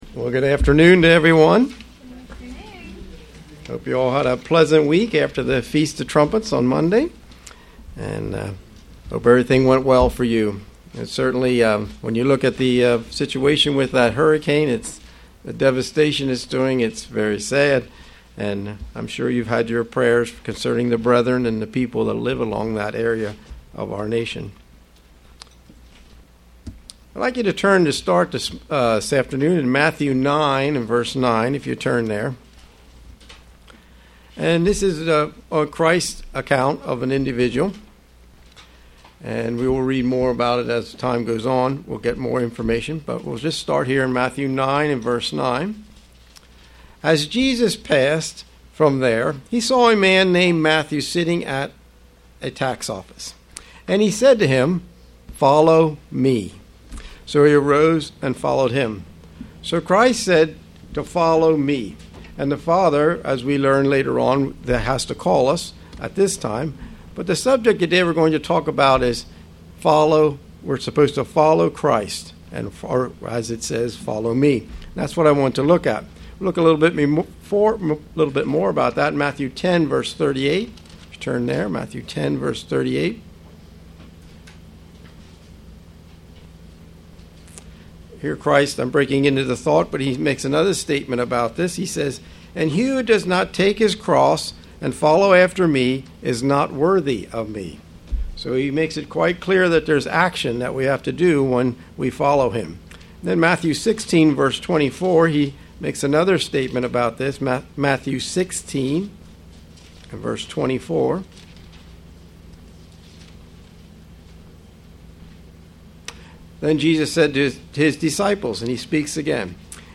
Sermons
Given in York, PA